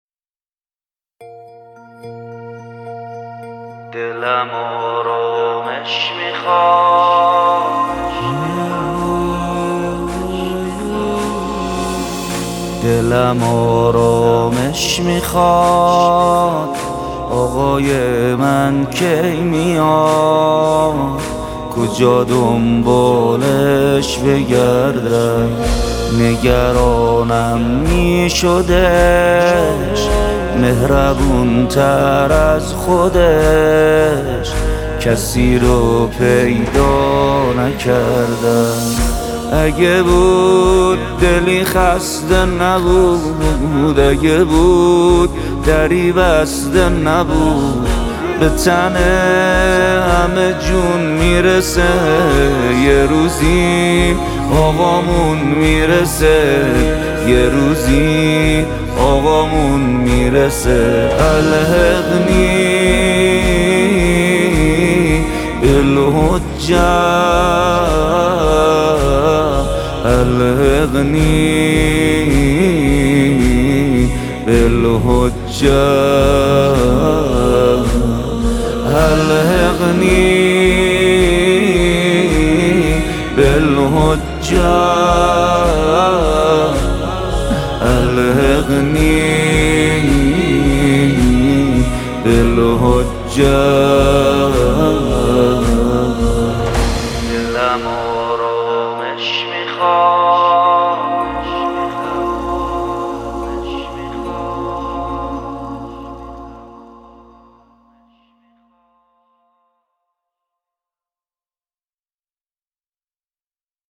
با صدای دلنشین